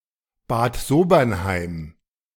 Bad Sobernheim (German pronunciation: [baːt ˈzoːbɐnˌhaɪm]
De-Bad_Sobernheim.ogg.mp3